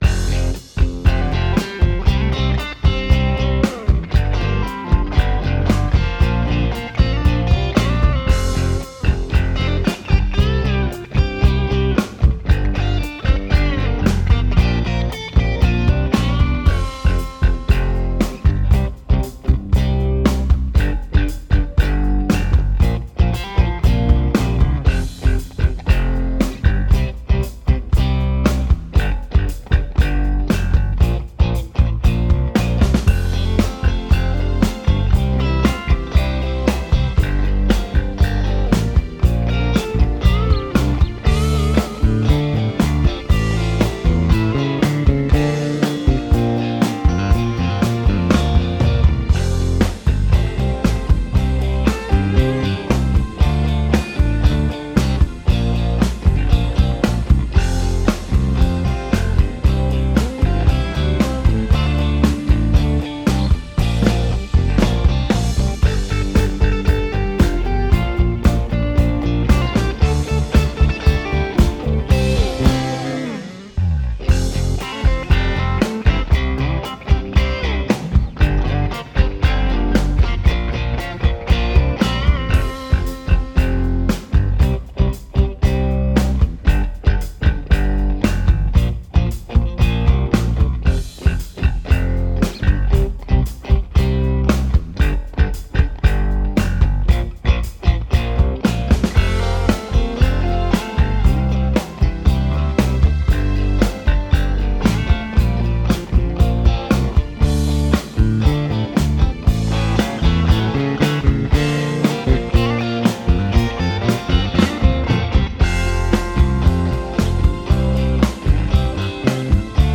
Fender '65 Deluxe Reverb